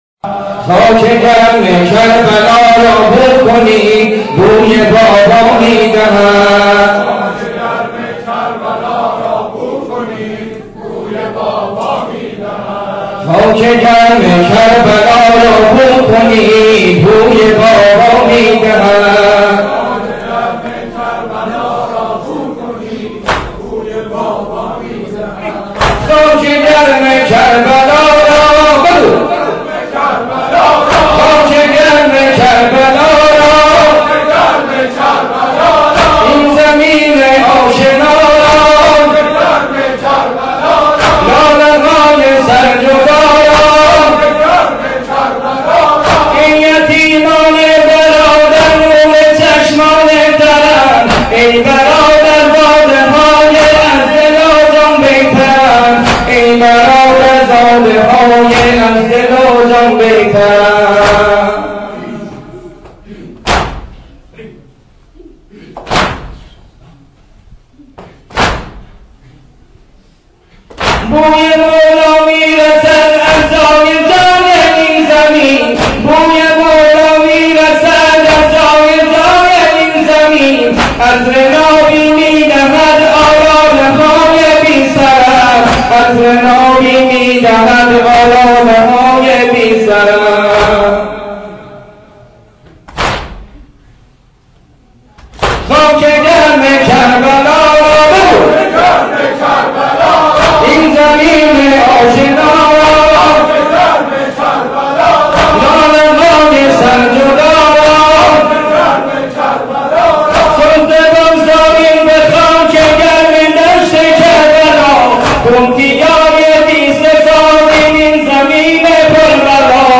متن و سبک نوحه اربعین -( خاک گرم کربـلا را بو کنید بوی بابا می دهد )